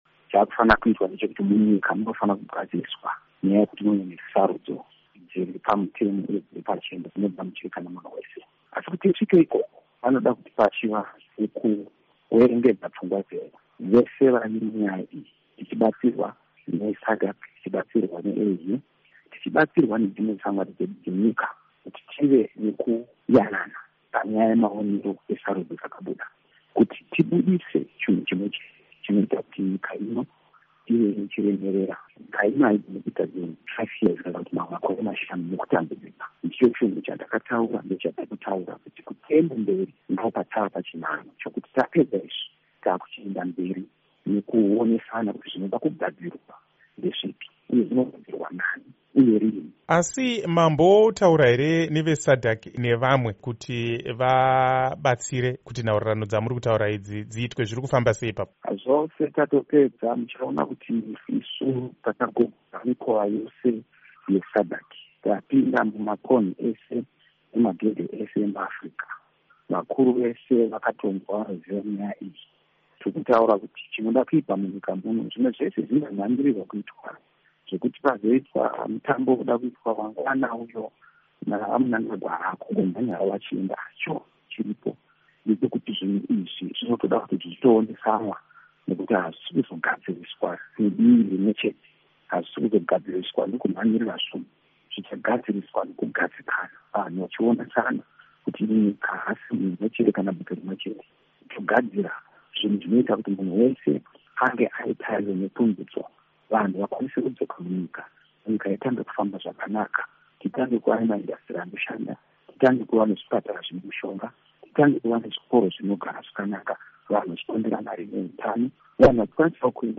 Hurukuro naVaNelson Chamisa